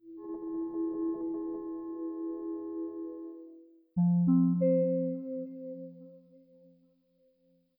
Windows X15 Startup.wav